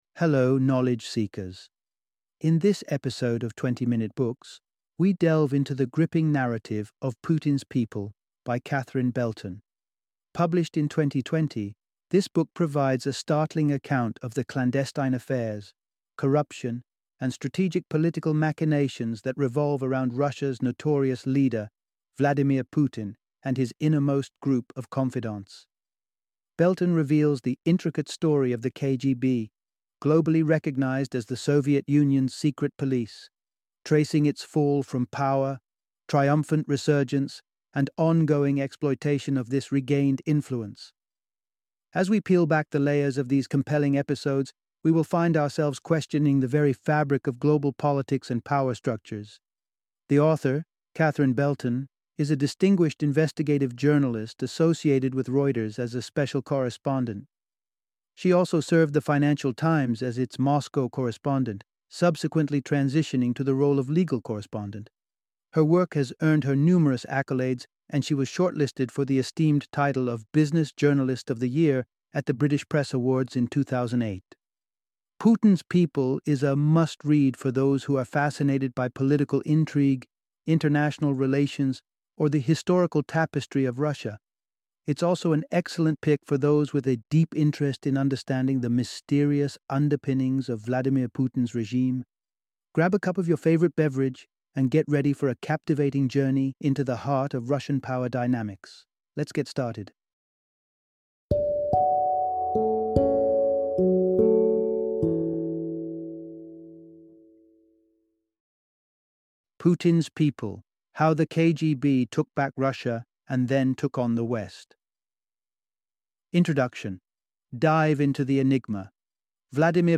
Putin's People - Audiobook Summary